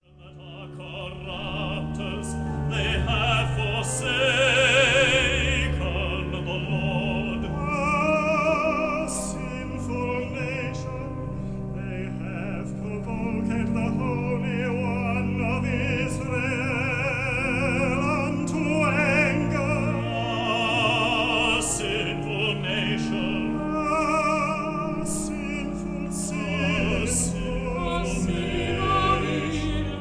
counter-tenor
tenor
baritone
organ